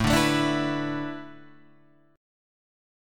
Asus2sus4 chord